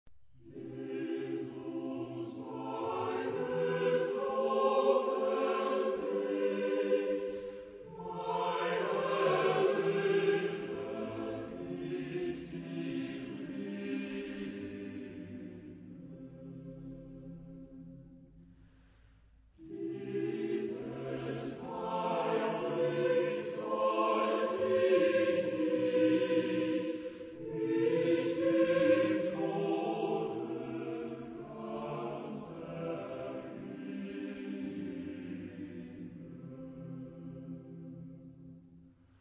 Genre-Stil-Form: geistlich ; romantisch ; Choral
Charakter des Stückes: inbrünstig
Chorgattung: SATB  (4 gemischter Chor Stimmen )
Instrumente: Orgel (1)
Tonart(en): C-Dur